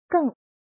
怎么读
gèng
geng4.mp3